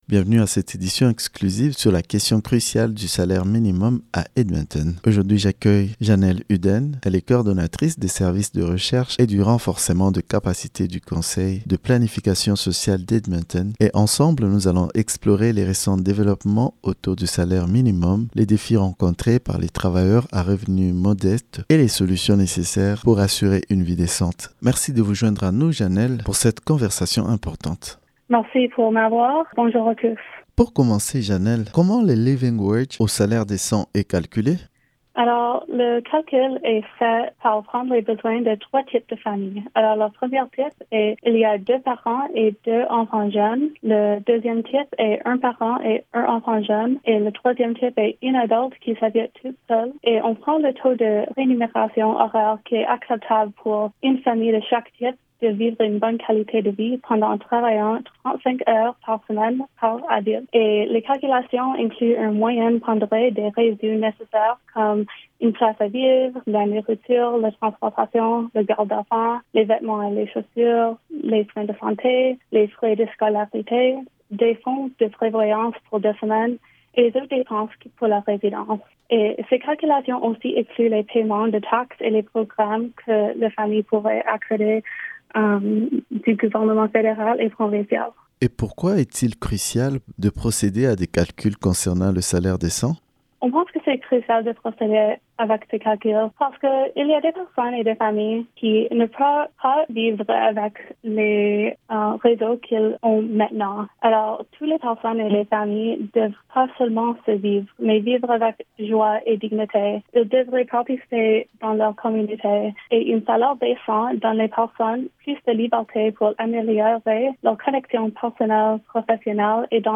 L’entrevue